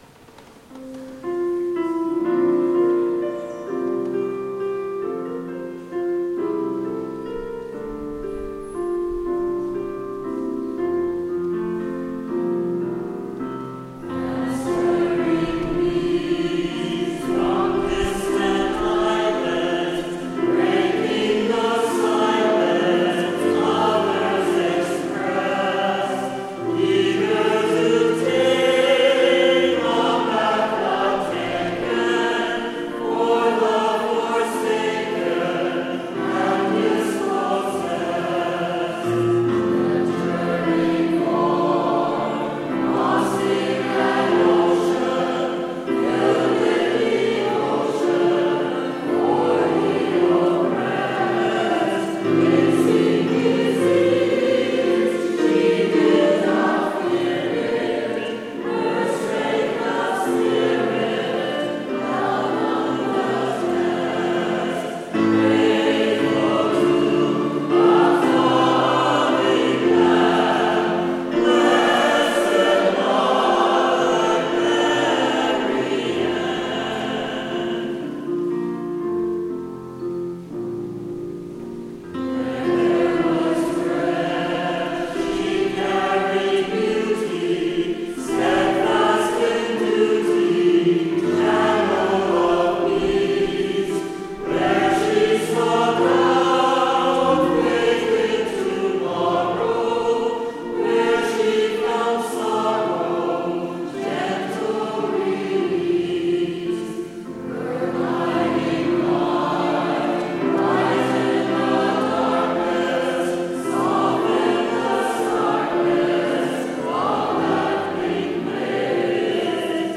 Voice | Downloadable